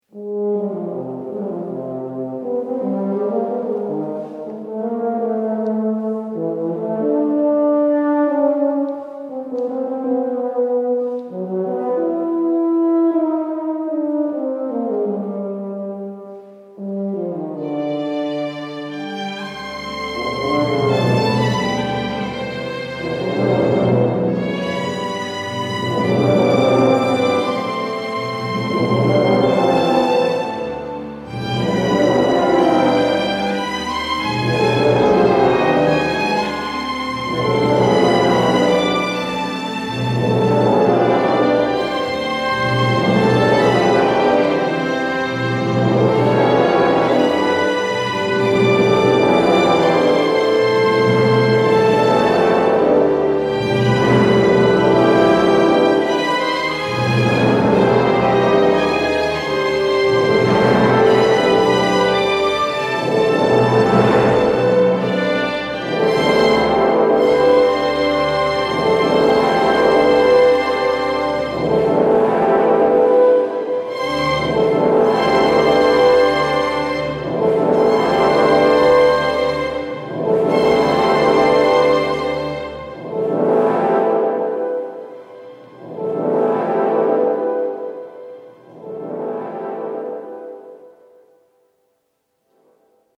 Concerto for Euphonium and Loopstation. Recorded live at Christ Church, Skipton on 8 November 2014